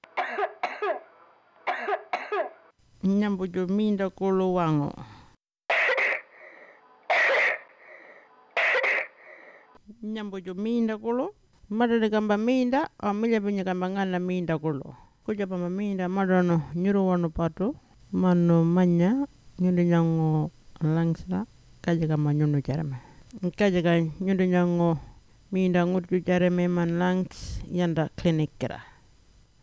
Cough Audio – Walpiri
20048_dry-&-wet-cough-warlpiri-final.wav